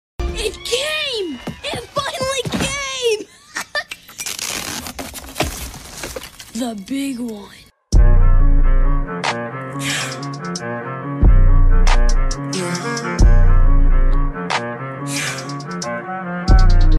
Explore our versatile Water Spray